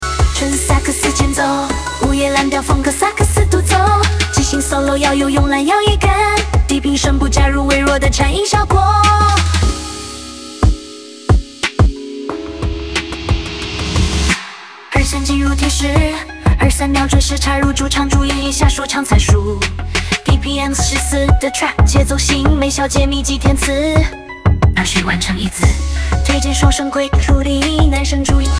【0-23秒】纯萨克斯前奏
- 午夜蓝调风格萨克斯独奏
- 即兴solo要有慵懒摇曳感
- 低频声部加入微弱的颤音效果
- BPM≥140 的Trap节奏型
- 推荐双声轨处理：男声主音+女声和声碎片
- 吐字要求：爆破音强化处理
人工智能生成式歌曲